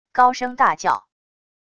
高声大叫wav音频